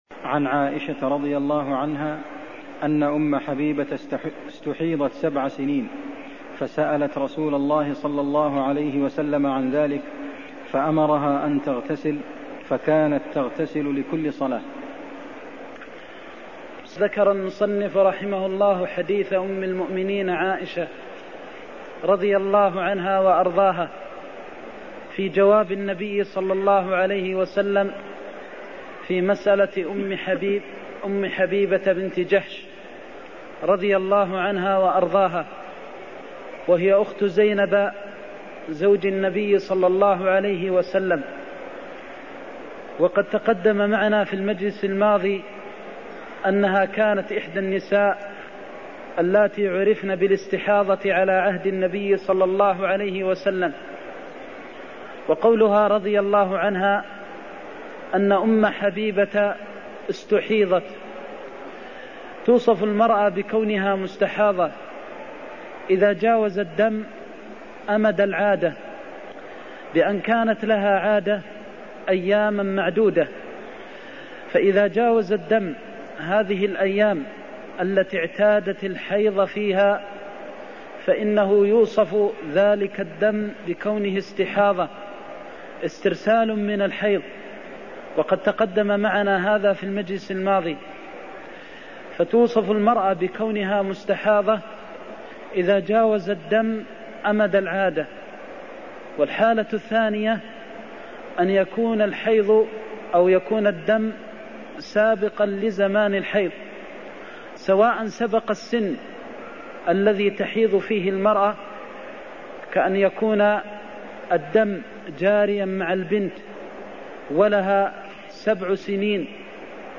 المكان: المسجد النبوي الشيخ: فضيلة الشيخ د. محمد بن محمد المختار فضيلة الشيخ د. محمد بن محمد المختار المستحاضة تغتسل لكل صلاة (39)